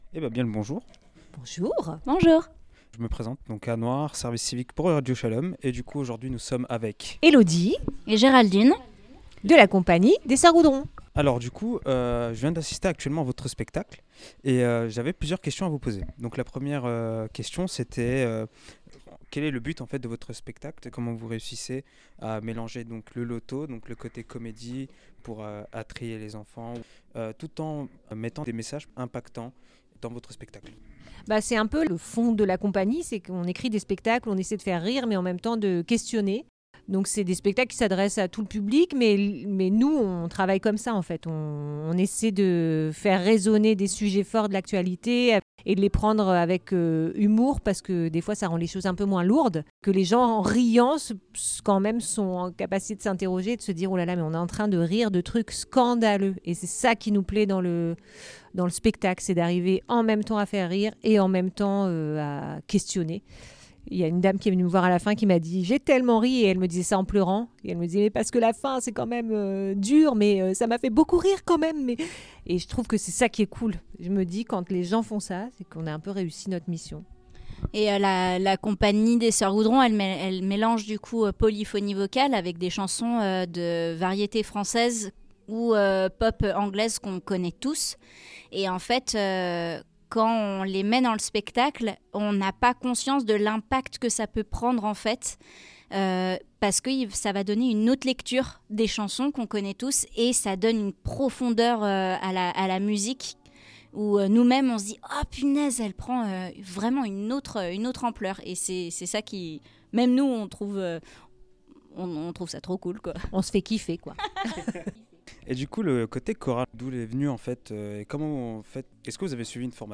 Interview de la Compagnie Les Sœurs Goudron
Interview